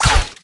jess_dry_fire_01.ogg